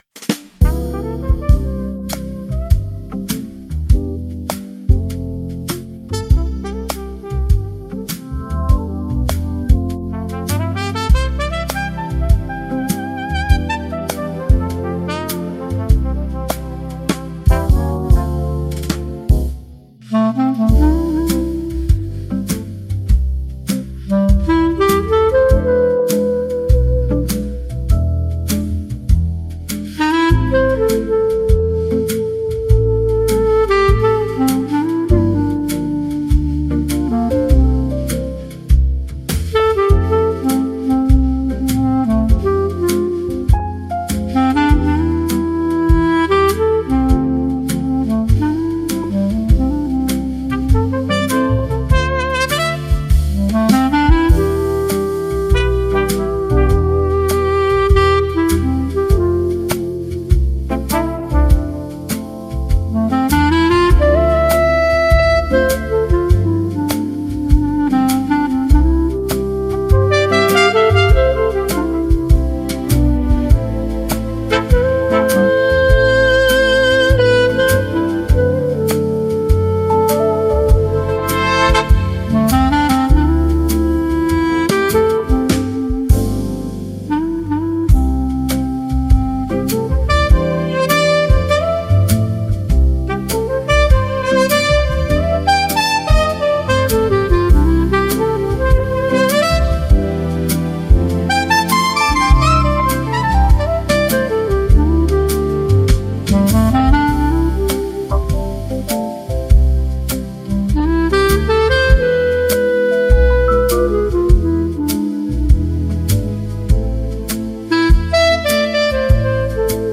I created an instrumental version of the above song with a Clarinet solo.
Easy listening and soothing to the soul!  🙂